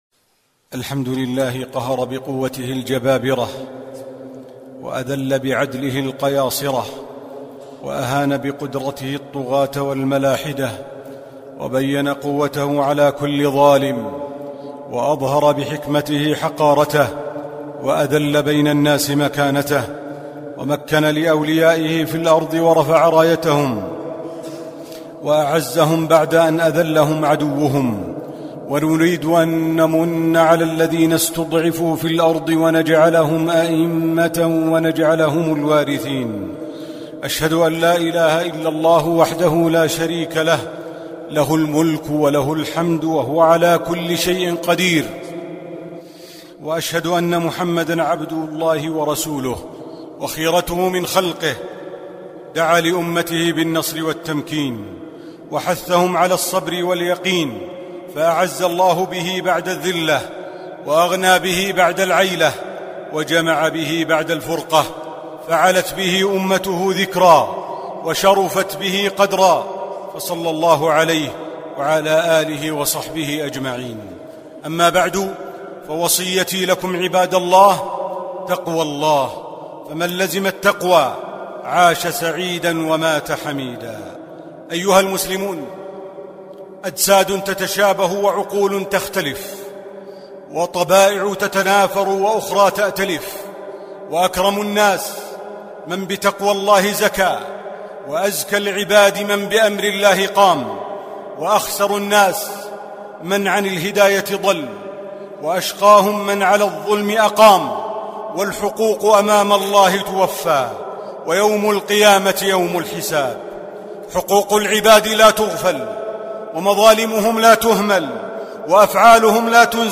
الخطب الصوتية